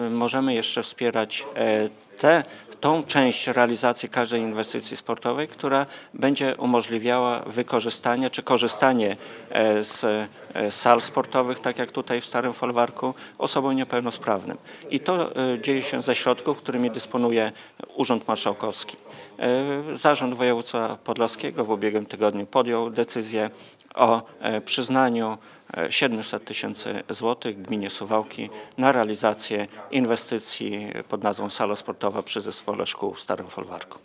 – Dzięki funduszom na rzecz rehabilitacji osób niepełnosprawnych mogliśmy przeznaczyć na budowę hali dodatkowe 700 tysięcy złotych  – mówi Bogdan Dyjuk, członek Zarządu Województwa Podlaskiego.